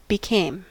Ääntäminen
Ääntäminen : IPA : /bɪˈkeɪm/ US : IPA : [bɪˈkeɪm] Haettu sana löytyi näillä lähdekielillä: englanti Käännöksiä ei löytynyt valitulle kohdekielelle. Became on sanan become imperfekti.